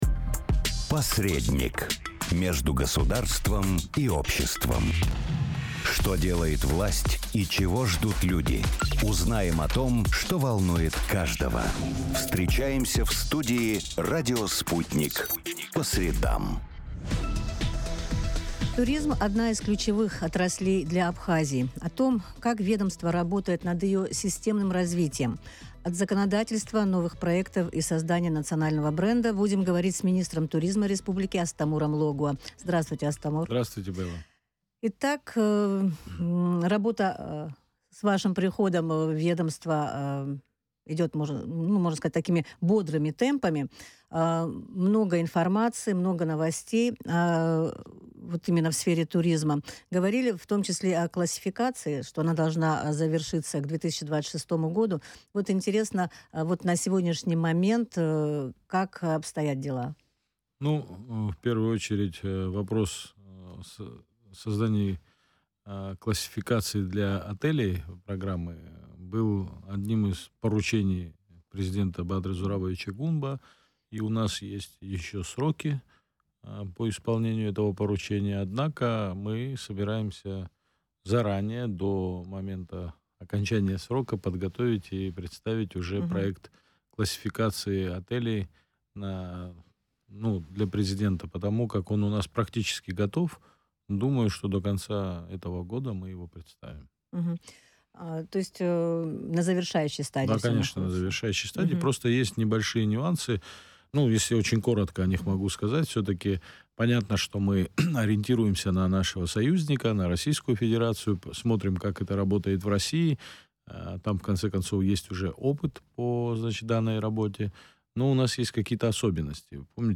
Туризм — одна из ключевых отраслей для Абхазии. О том, как государство работает над ее системным развитием – от законодательства, новых проектов до создания национального бренда, говорили с главой профильного ведомства в эфире радио...